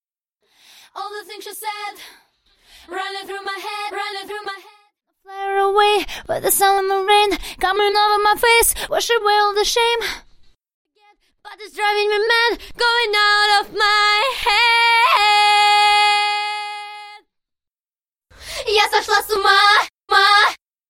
Studio Chorus Reply Vocals Stem
Studio Chorus Vocals Stem